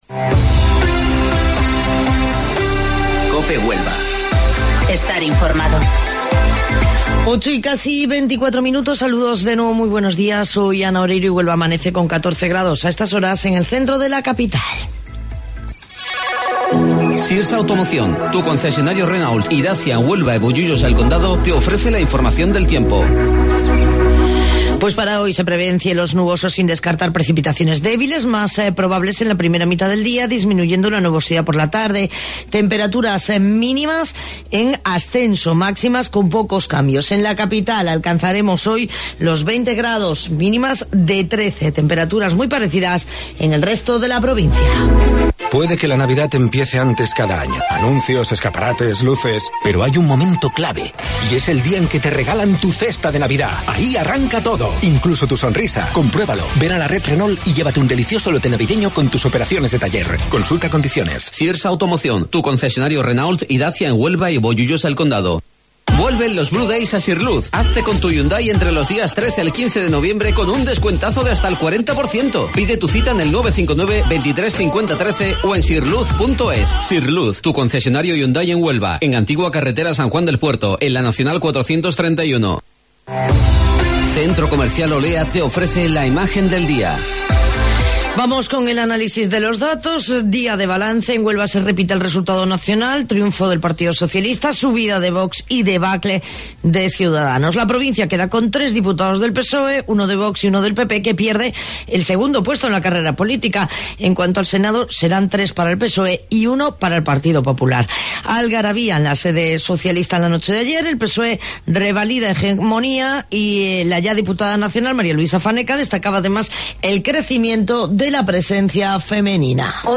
AUDIO: Informativo Local 08:25 del 11 de Noviembre